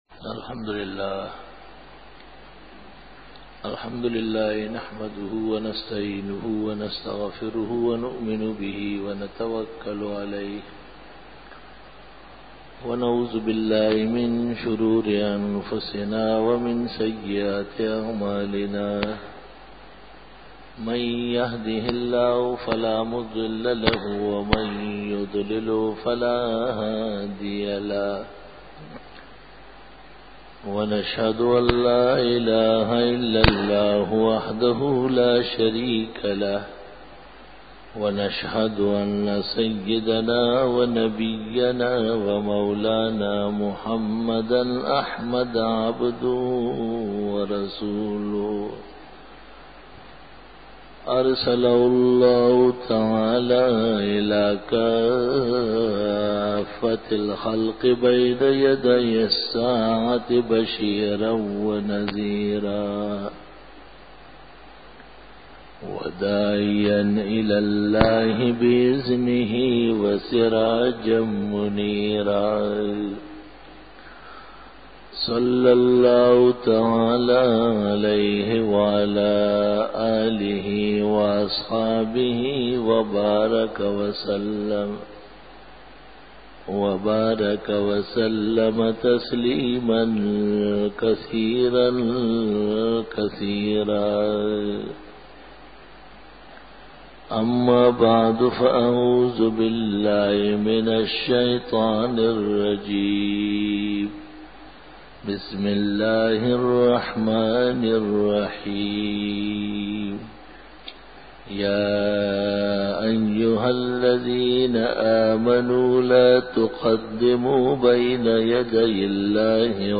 020_Jummah_Bayan_24_May_2002
بیان جمعۃ المبارک